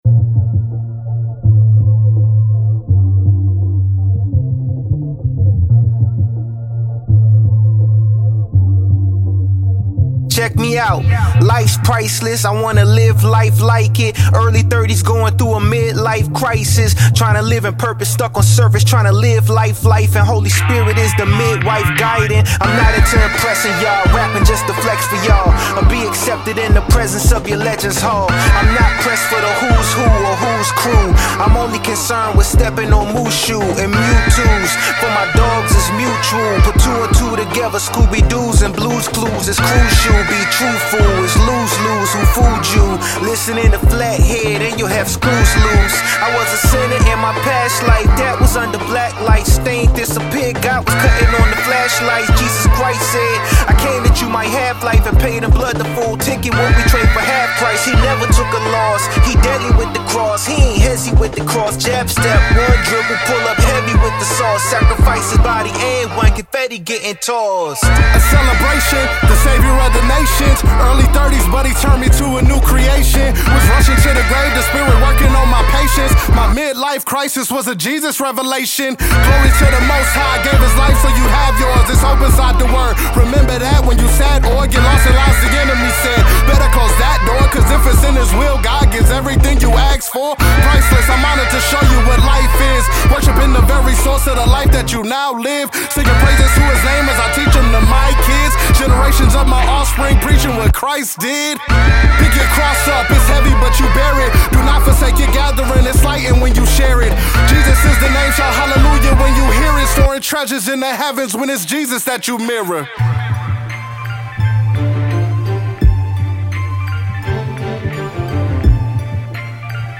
hip-hop
raw, introspective, and intentional